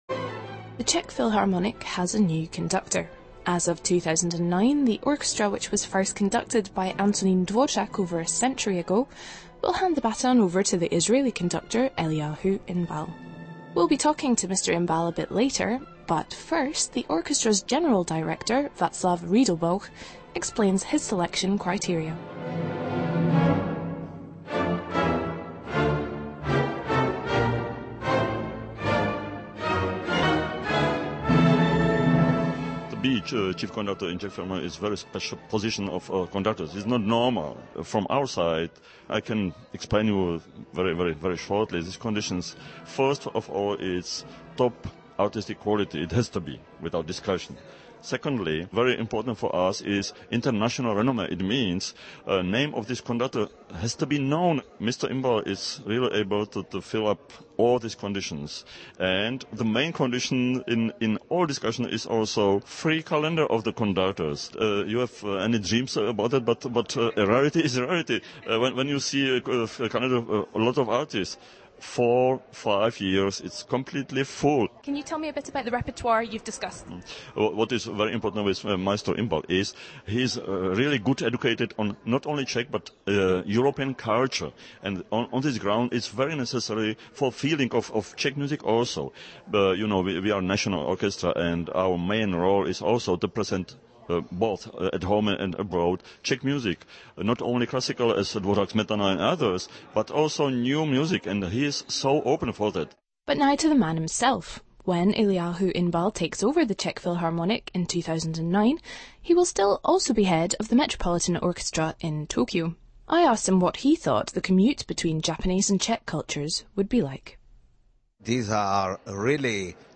el–ee–AH–hu (with a very light, secondary accent on the first syllable)
Since this podcast includes an interview with Mr. Inbal himself, I imagine it's authoritative.